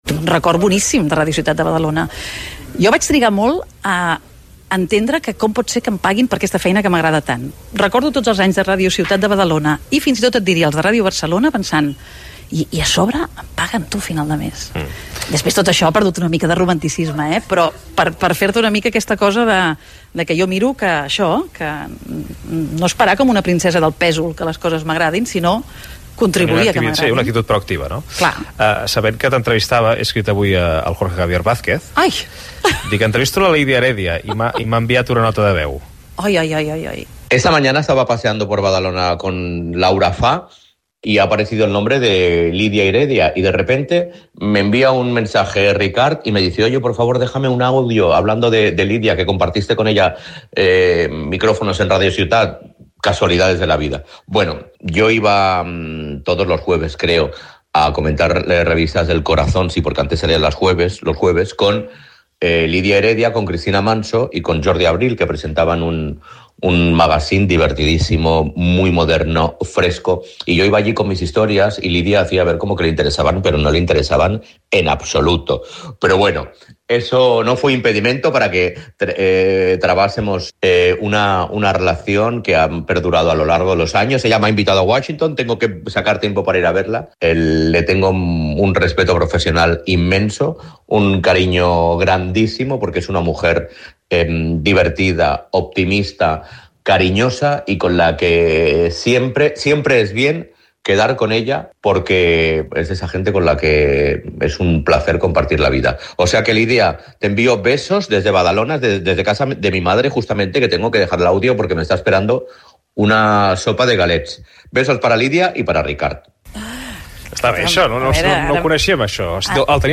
El Matí de Catalunya Ràdio: Conversa amb Ricard Ustrell - Catalunya Ràdio, 2025